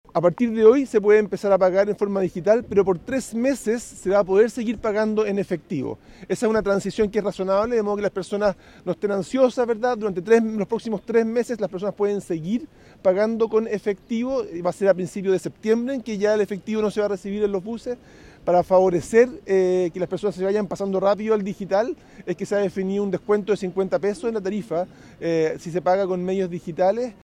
El ministro de Transportes y Telecomunicaciones, Juan Carlos Muñoz, indicó que durante la marcha blanca, utilizando los medios digitales, con el fin de incentivar a los usuarios, existirá un descuento del valor del pasaje de 50 pesos.